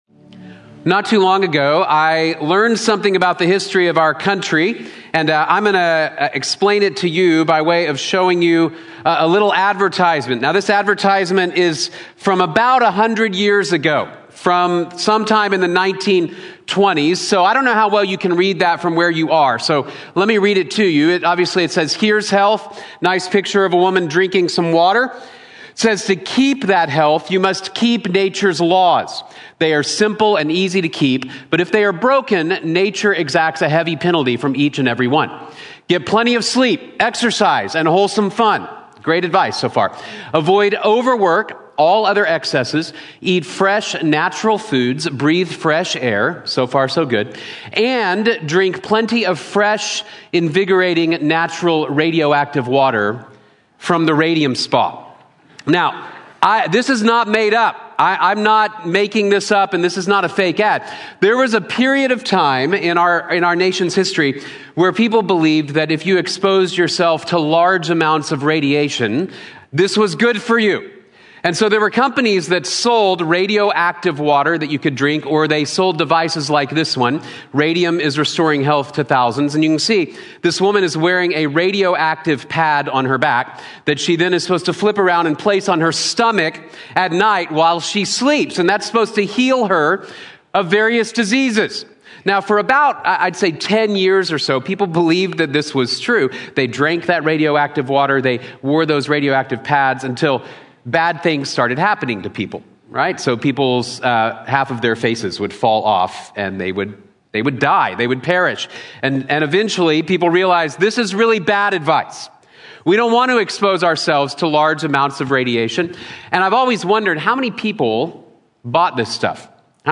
Voices of Freedom | Sermon | Grace Bible Church